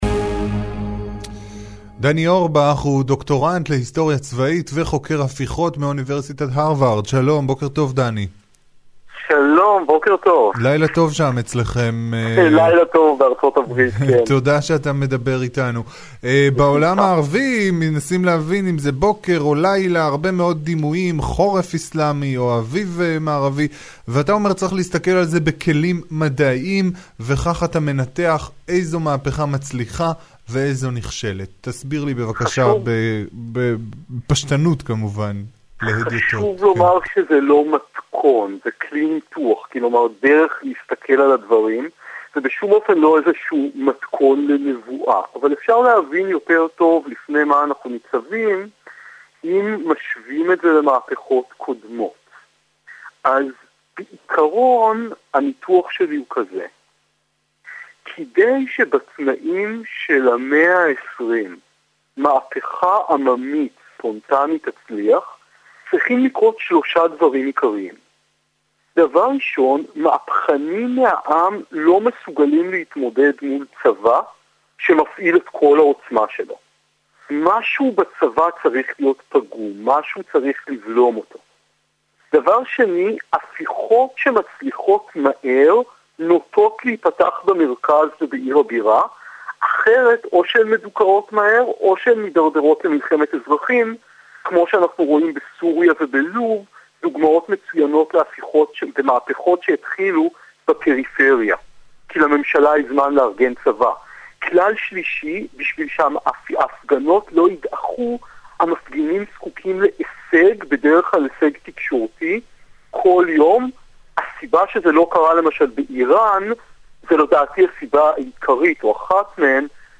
לאחרונה התראיינתי ברדיו "גלי ישראל" על המצב במצרים.